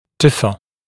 [‘dɪfə][‘дифэ]отличаться, различаться